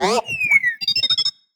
happy4.ogg